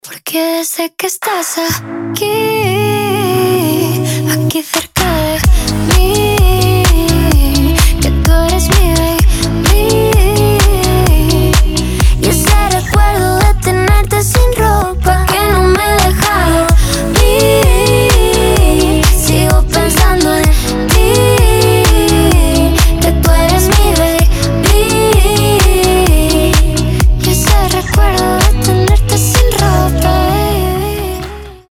• Качество: 320, Stereo
женский голос
Dance Pop